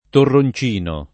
torroncino [ torron ©& no ] s. m.